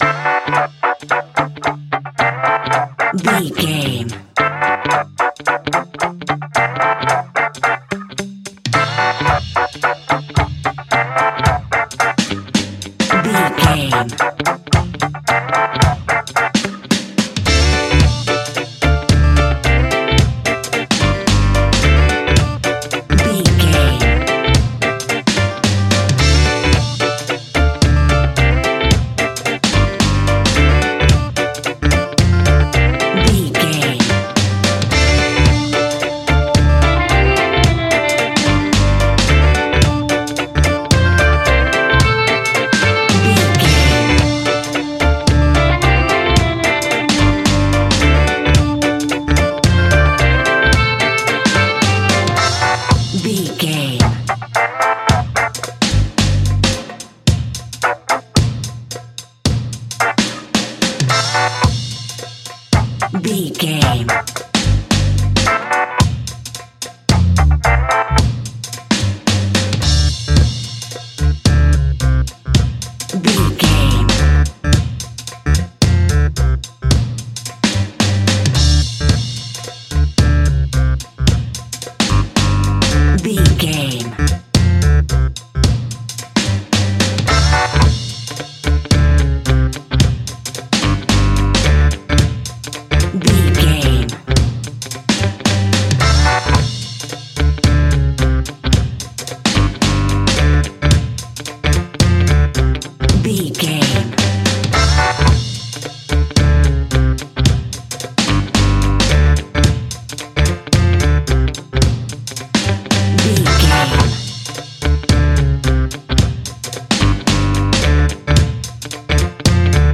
The hot summer reggae sounds!
Aeolian/Minor
instrumentals
laid back
chilled
off beat
drums
skank guitar
hammond organ
percussion
horns